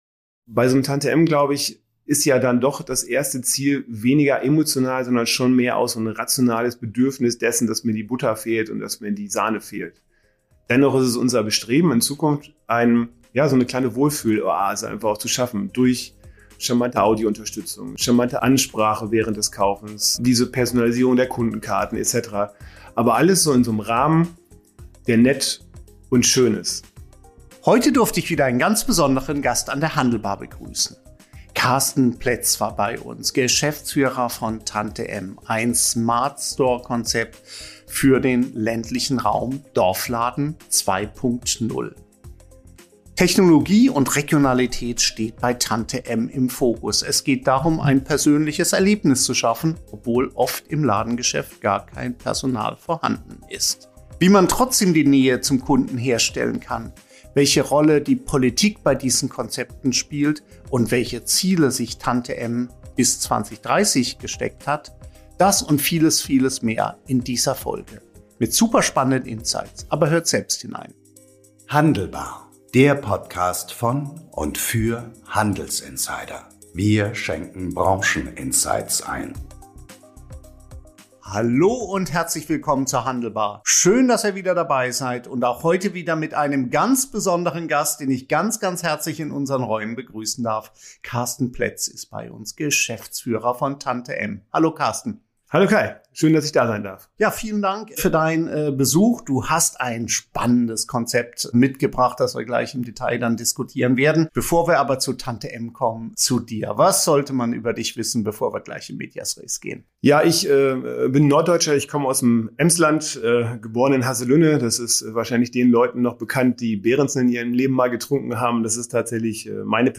Von alten Bankfilialen zu modernen Treffpunkten – Tante-M verbindet Technologie mit Gemeinschaft und sichert die Nahversorgung an Orten, die als ausgestorben galten. Gemeinsam sprechen die beiden über das Franchisesystem, bargeldlose Konzepte und die Herausforderung, leere Standorte wiederzubeleben.